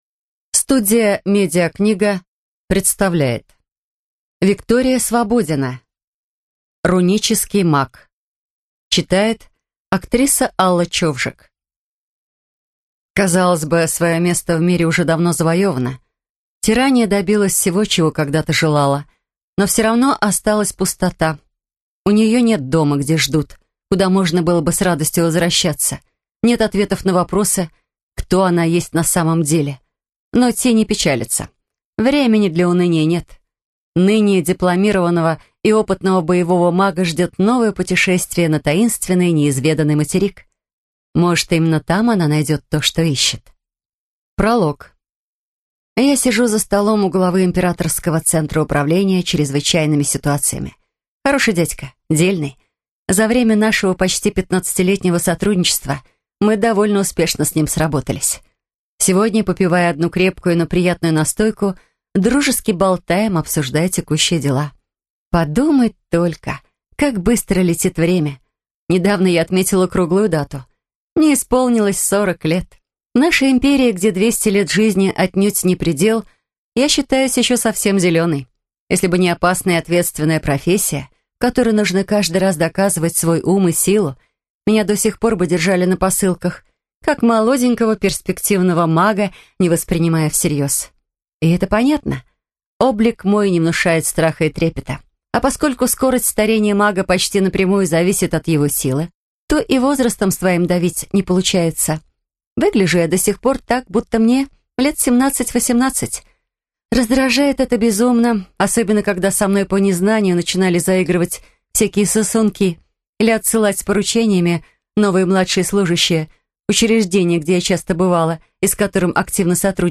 Аудиокнига Жизнь Тиррании. Рунический маг | Библиотека аудиокниг